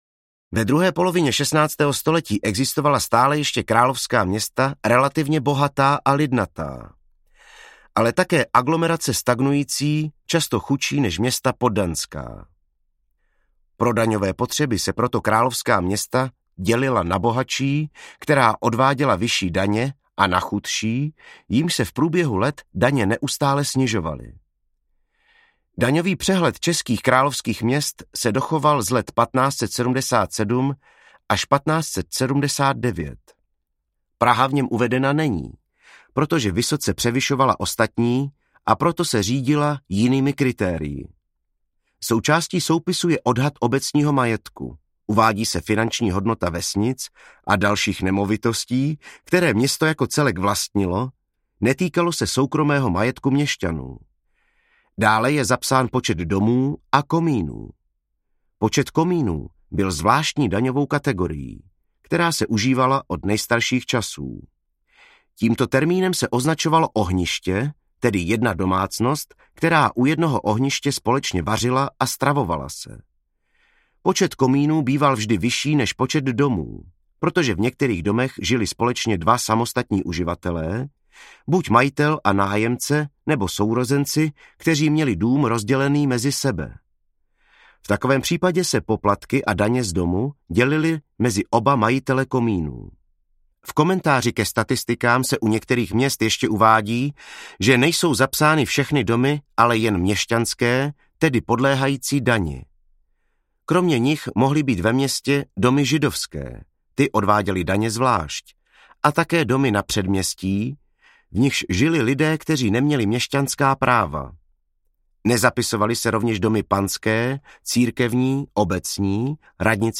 Život ve staletích - 16. století audiokniha
Ukázka z knihy
Vyrobilo studio Soundguru.